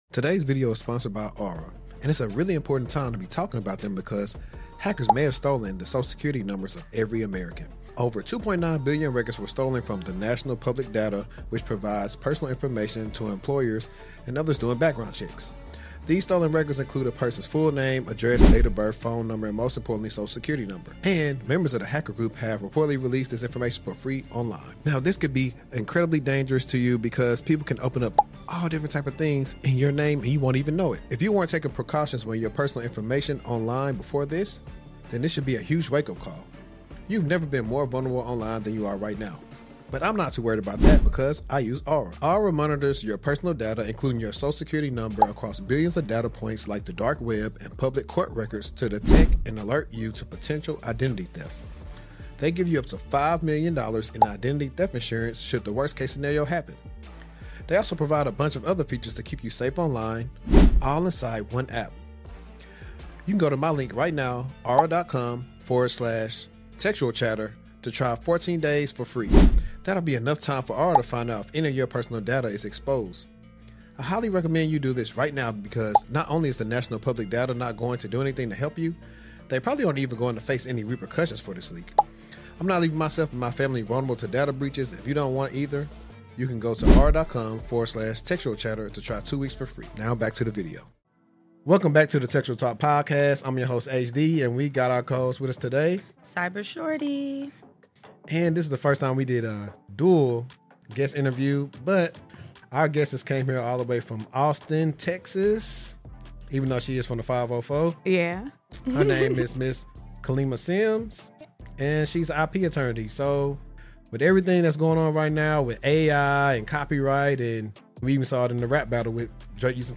and physics enthusiast—for a must-listen conversation